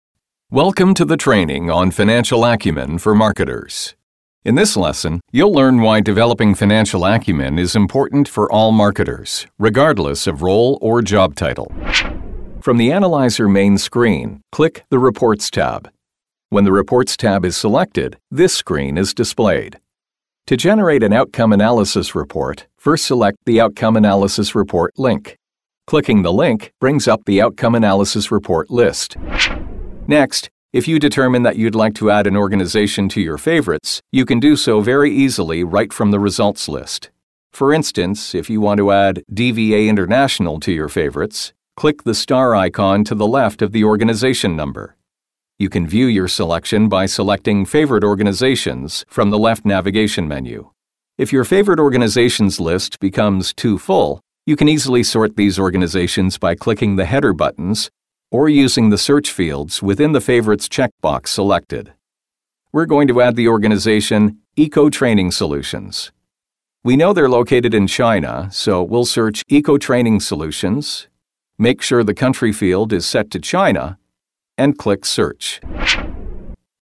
E-learning
Voz masculina "neutral en inglés norteamericano" (sin acento regional).
Micrófono Neumann TLM103